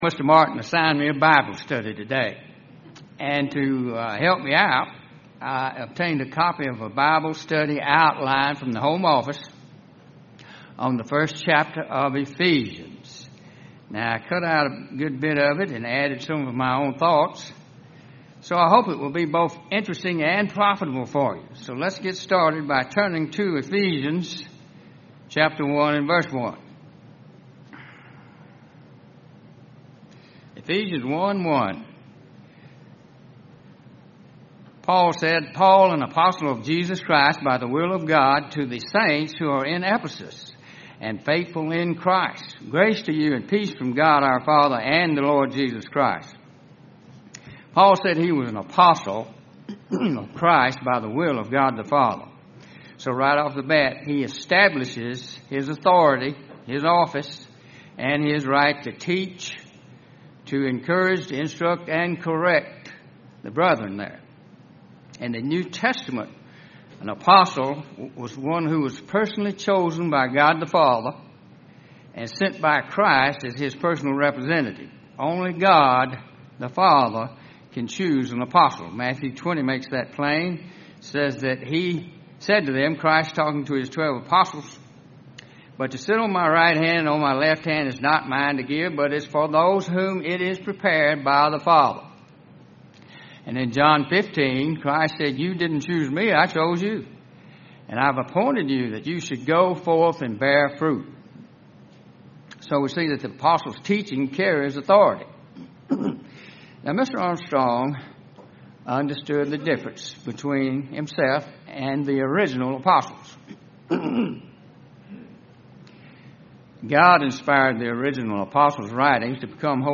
Bible study on Ephesus and the blessings of obeying God.